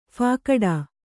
♪ phākaḍā